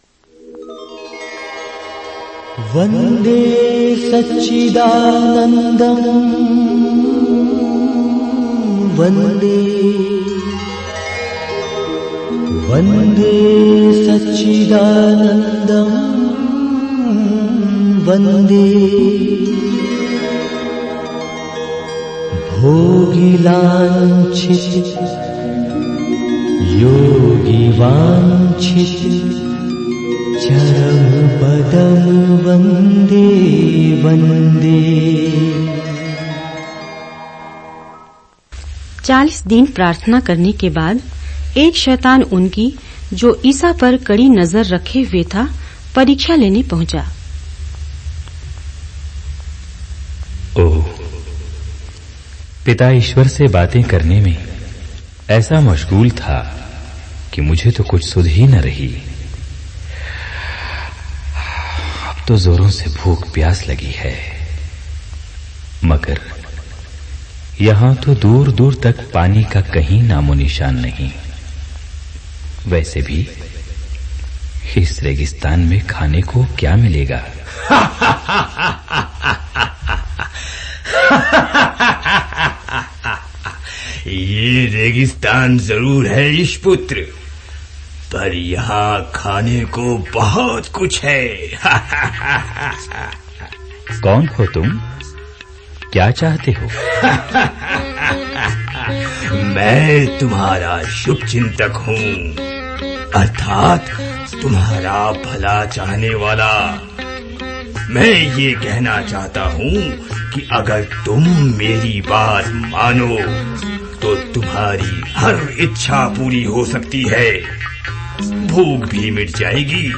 Bible Dramas